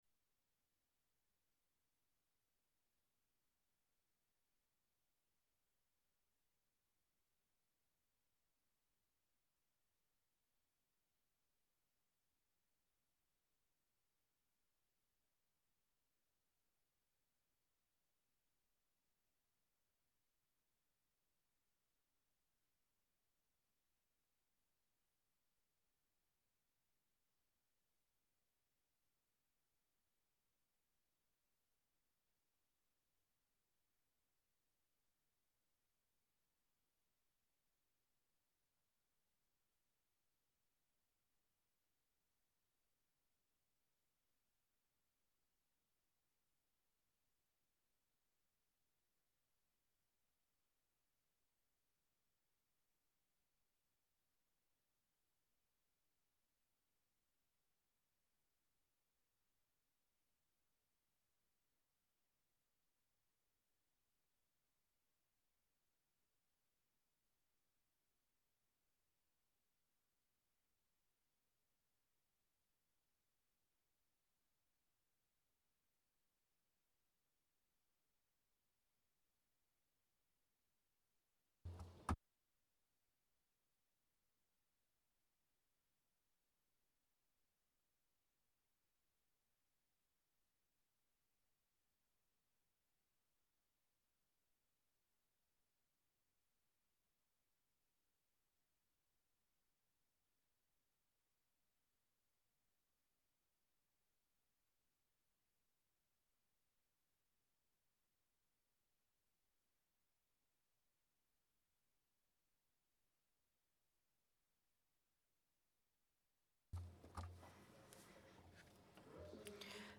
Opening remarks
by the Chair of the NATO Military Committee, Admiral Rob Bauer,the Norwegian Chief of Defence, General Eirik Kristoffersen and the Norwegian Minister of Defence, Bjørn Arild Gram at the meeting of the Military Committee in Chiefs of Defenc